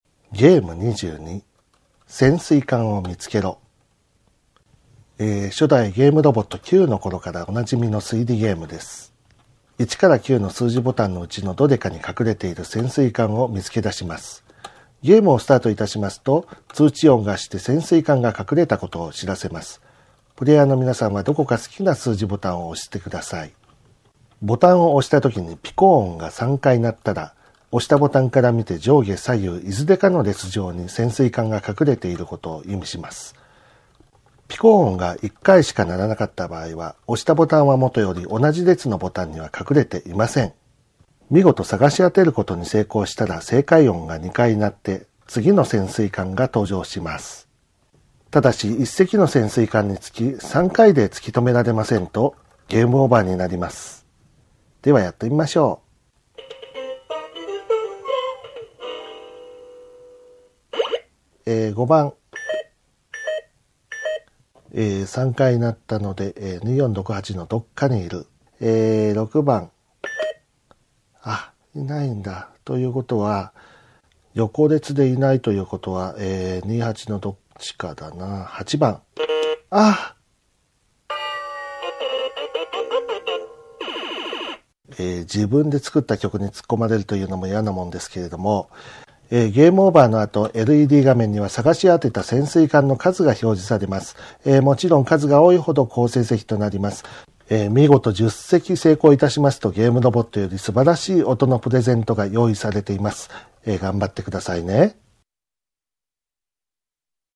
ゲームロボット５０の遊び方音声ガイド
遊び方の説明書は商品に同梱していますが、目の不自由な方にも遊んでいただけるよう音声による遊び方の説明をご用意しております。